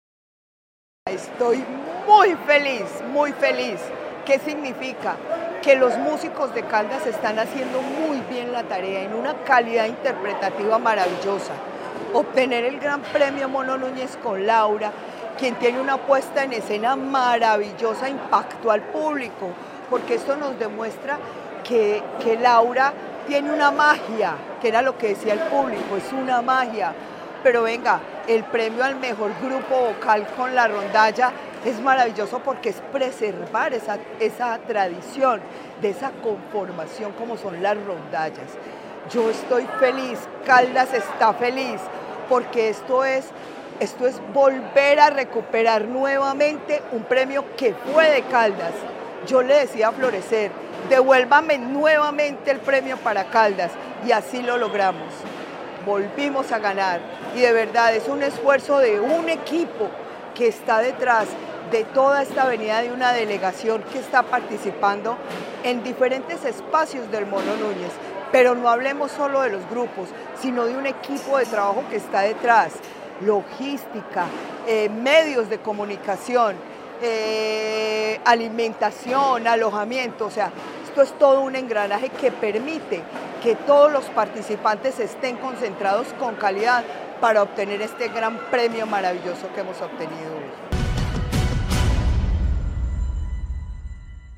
Luz Elena Castaño Rendón, secretaria de Cultura de Caldas